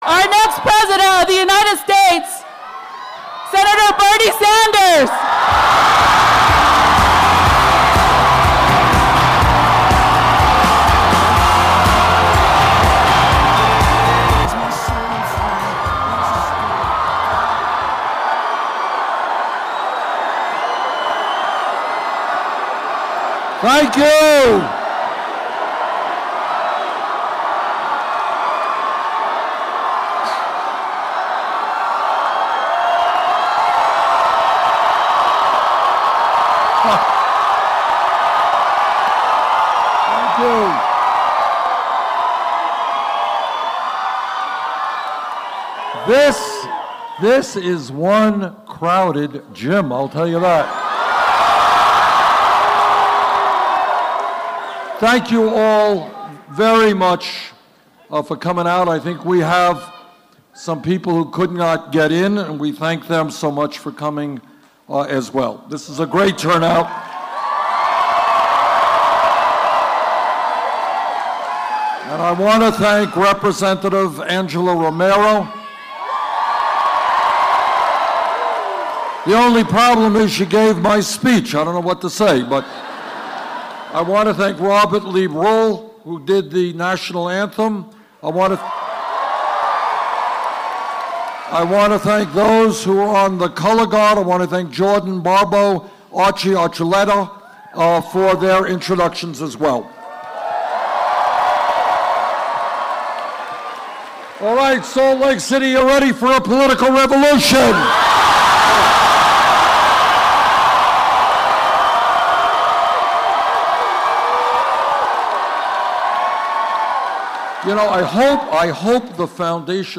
The presidential candidate makes a second campaign stop in The Beehive State as he tries to muster more support for tomorrow's presidential preference caucus vote. He thanked the crowd who came and talked about how he has a lead over Republican candidate Donald Trump in a conservative state like Utah.